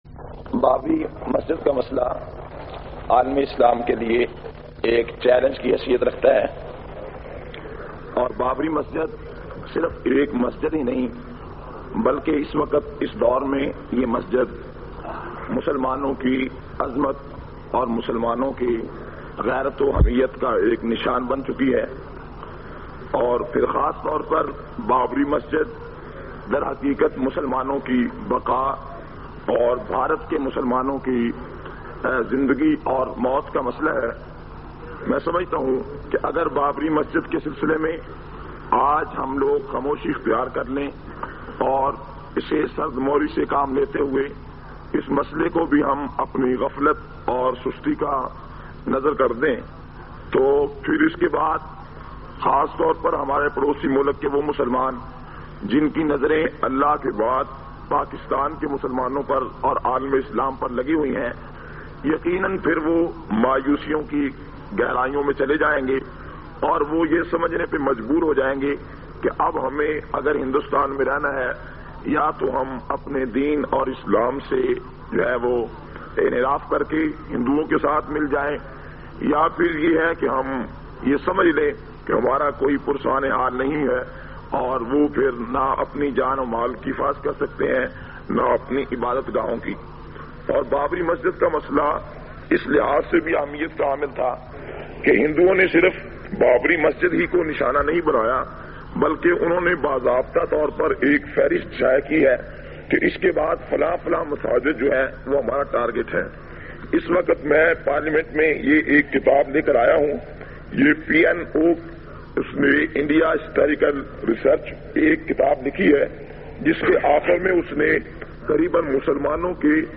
451- Qaomi Assembly Khutbat Vol 5.mp3